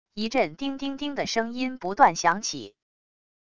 一阵叮叮叮的声音不断响起wav音频